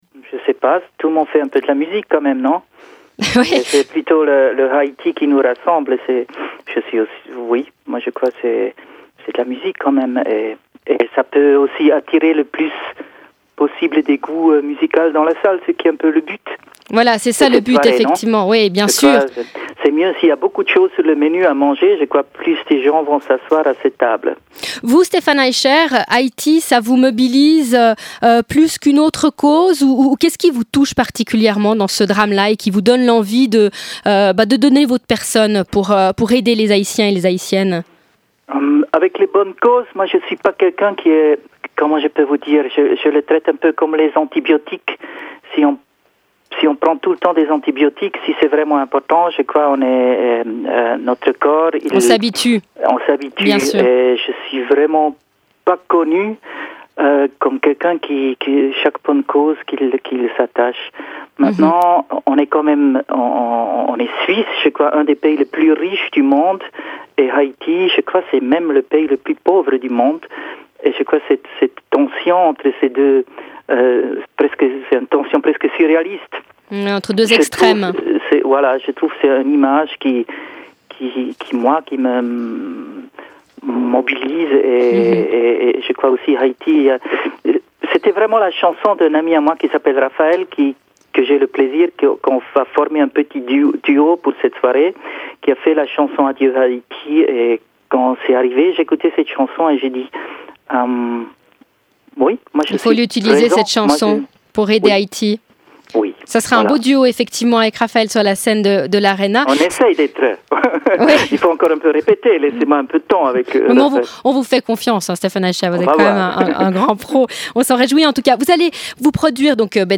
Stephan Eicher, chanteur